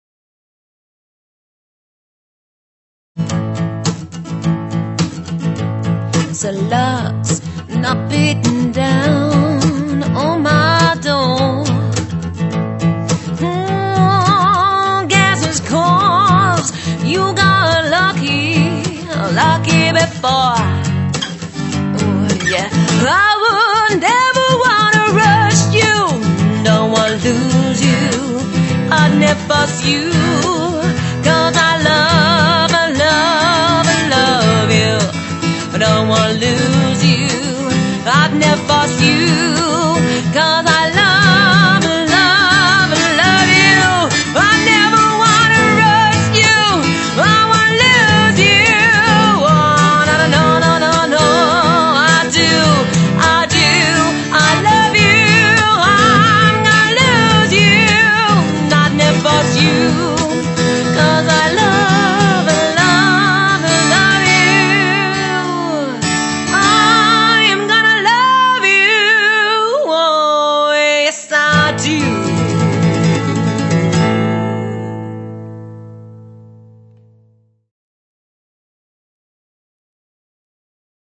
singing and playing acoustic guitar as a solo performer